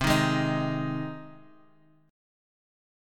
Cm#5 chord